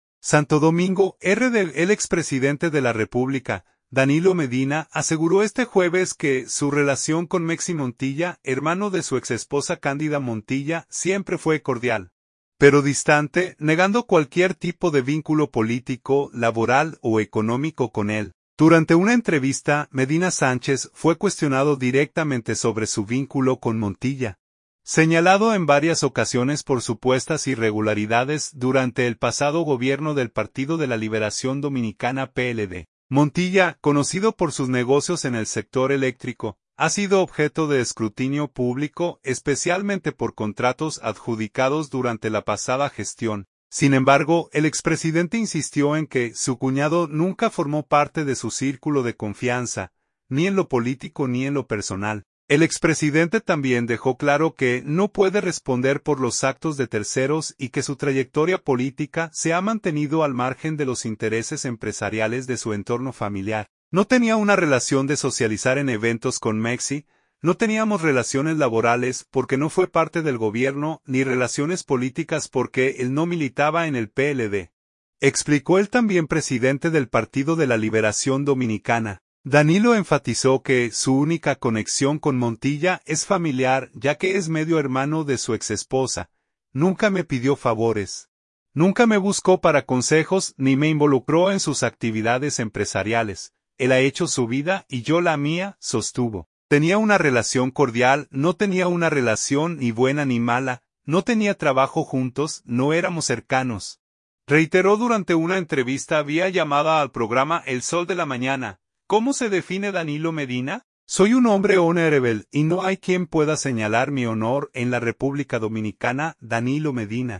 “Tenía una relación cordial, no tenía una relación ni buena ni mala, no tenía trabajo juntos, no éramos cercanos, reiteró durante una entrevista vía llamada al programa El Sol de la Mañana.